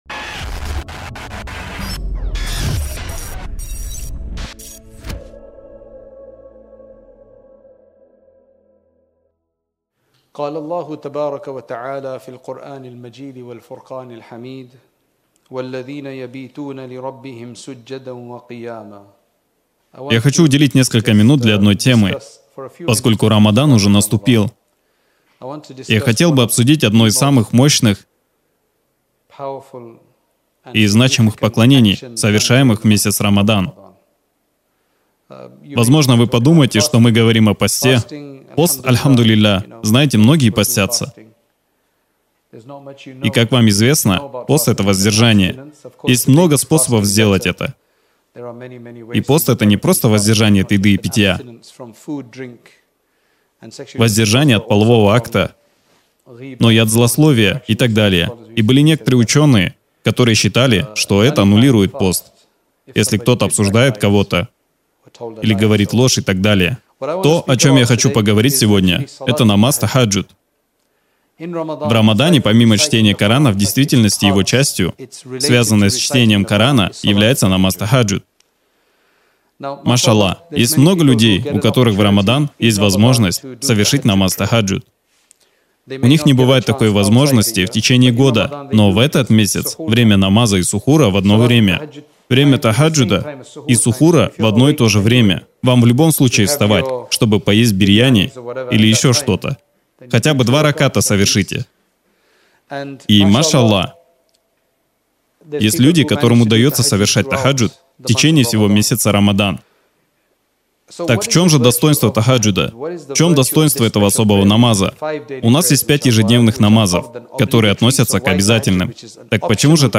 Лекция о достоинствах тахаджуд намаза, совершаемом по Сунне в третью часть ночи.